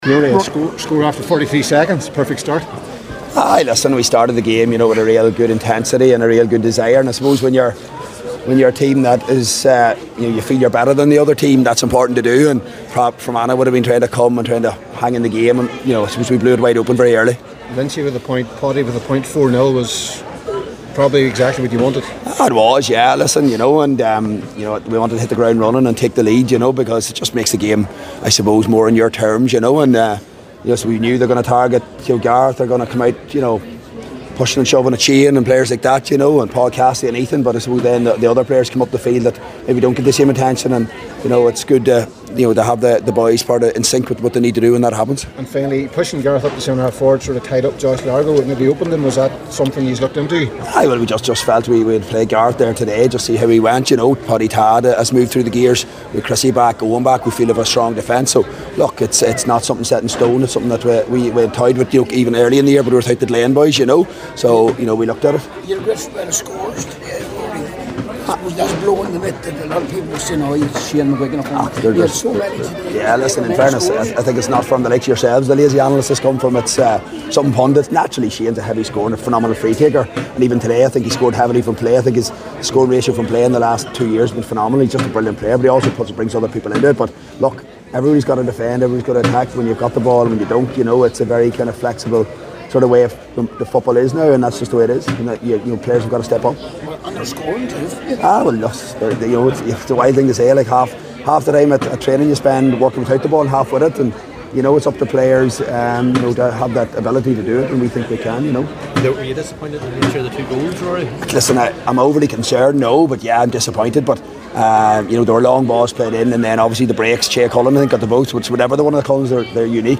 Derry boss Rory Gallagher spoke with the media after the game…